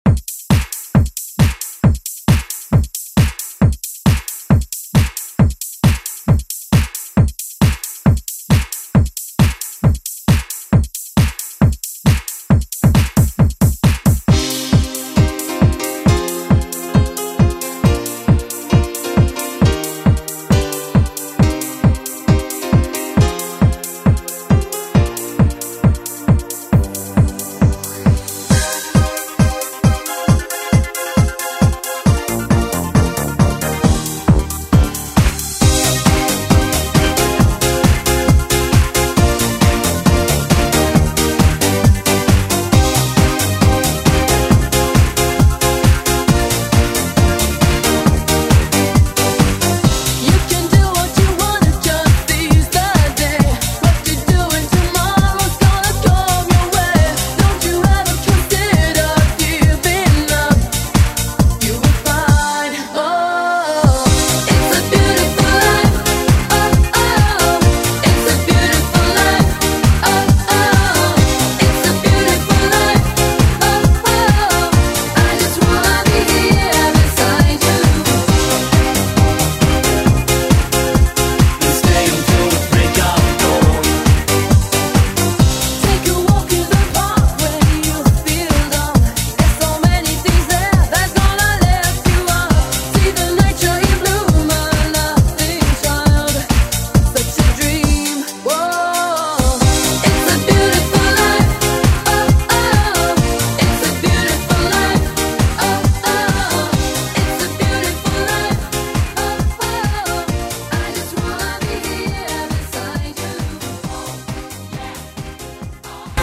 Throwback Electronic Pop Rock Music
Extended ReDrum
130 bpm
Genre: 80's